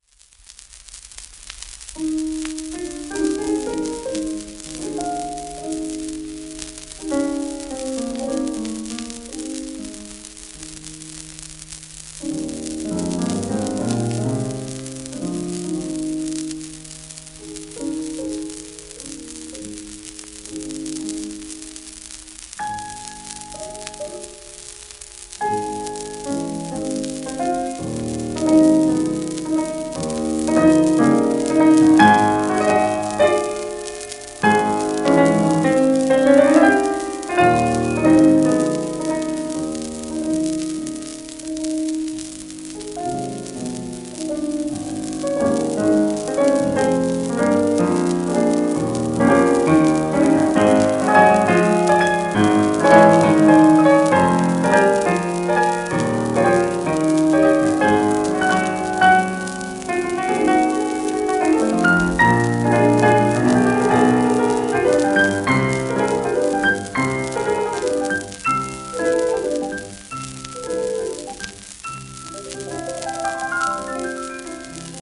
1939年頃録音